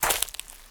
STEPS Leaves, Walk 06.wav